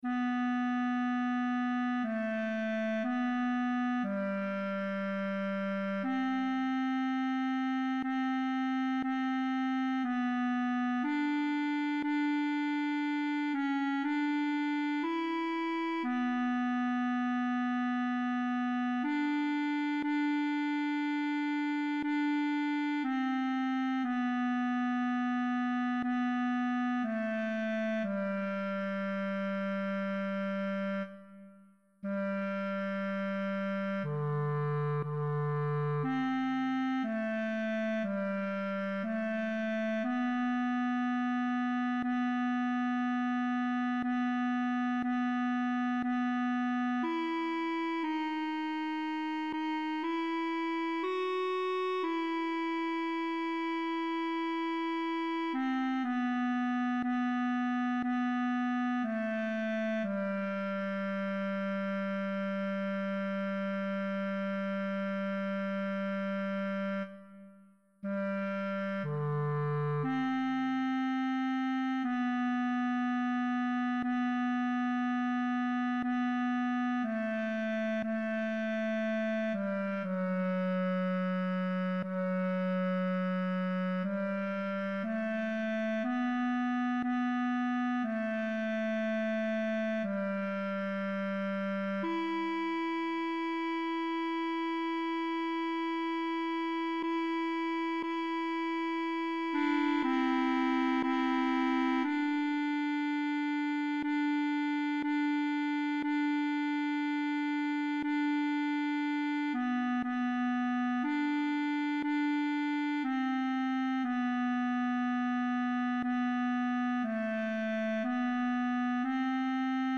Partitura, cor mixt (pdf): Tatăl nostru – A. Pann
tenor, bas, cor mixt